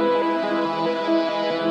SaS_MovingPad03_140-E.wav